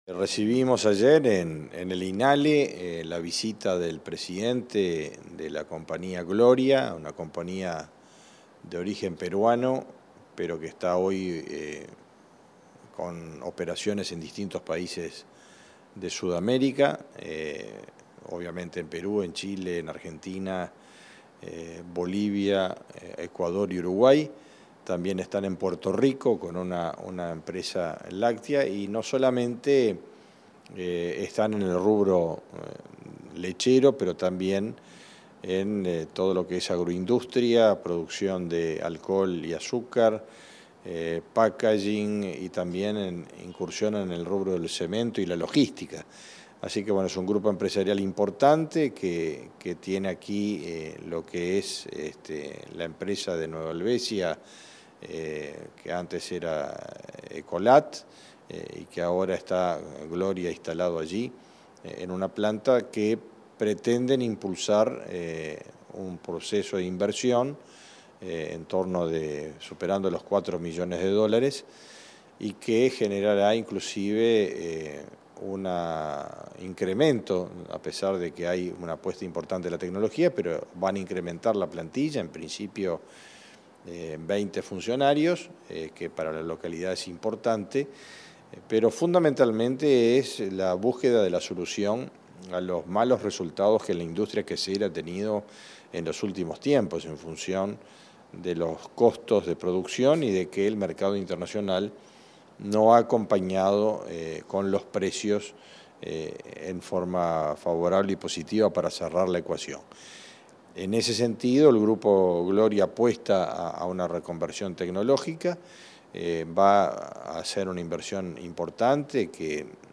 Entrevista al ministro de Ganadería, Fernando Mattos